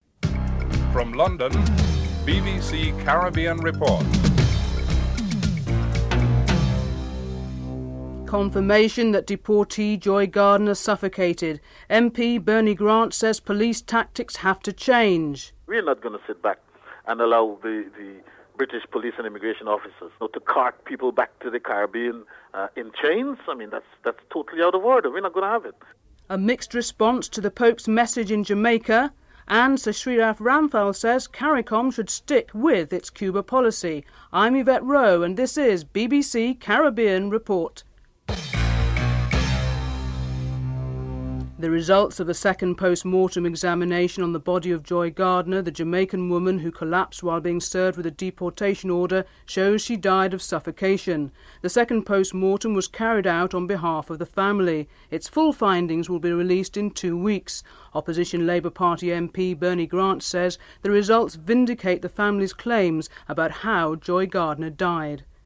1. Headlines (00:00-00:39)
Interview with Sir Shridath Ramphal, former Head, West Indian Commission (08:14-10:45)